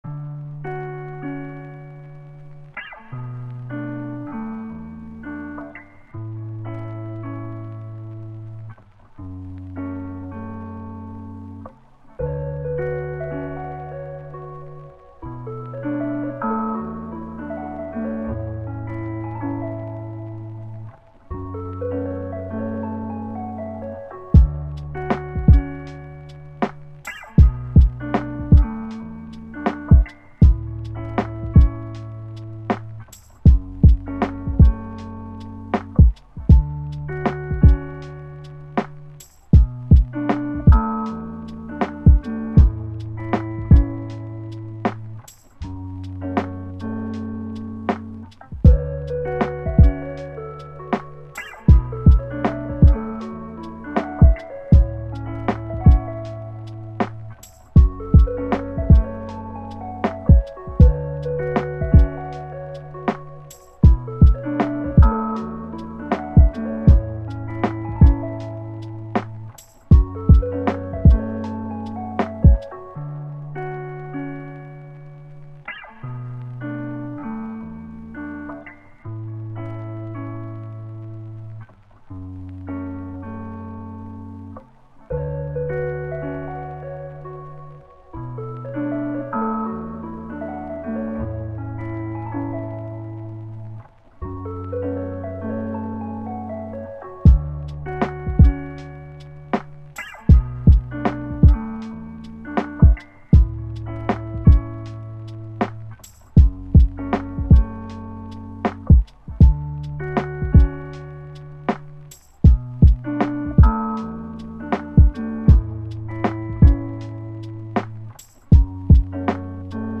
ژانر : لوفای مود : چیل تمپو : 158 زمان